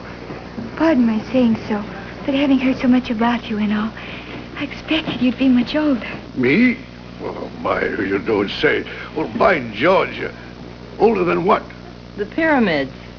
My favorite funny moviescenes on wav file!